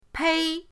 pei1.mp3